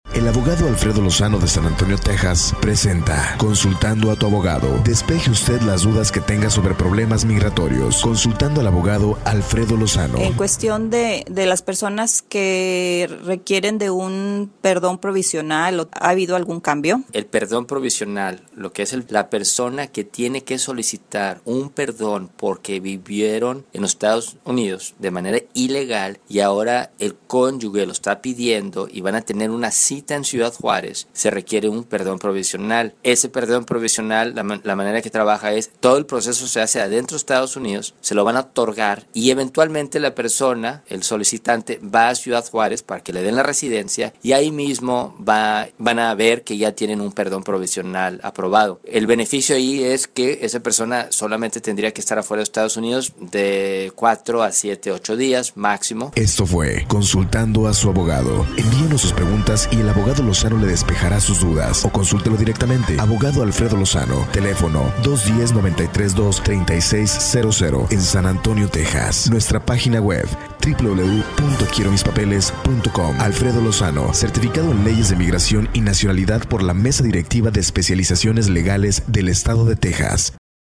ALL RADIO INTERVIEWS ARE IN SPANISH